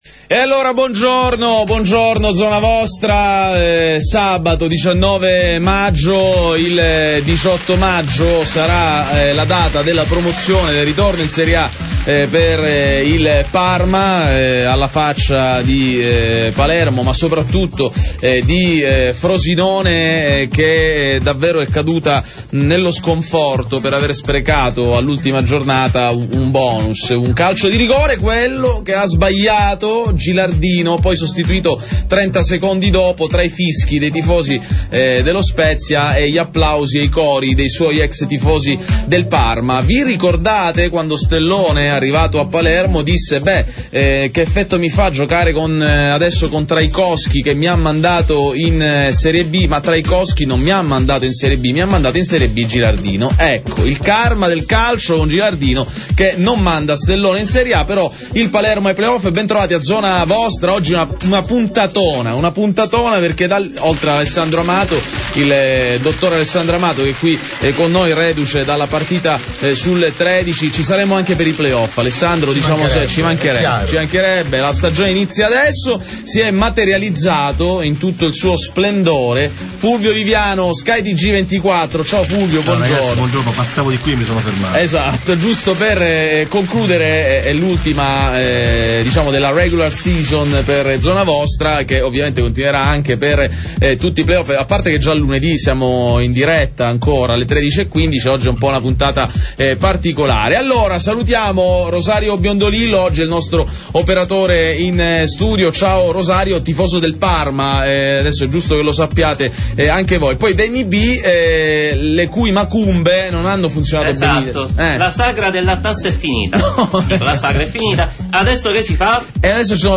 la partita è finita 0 a 2. Adesso è il momento dei PlayOff. Nostro ospite in studio